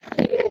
Minecraft Version Minecraft Version 25w18a Latest Release | Latest Snapshot 25w18a / assets / minecraft / sounds / mob / endermen / idle2.ogg Compare With Compare With Latest Release | Latest Snapshot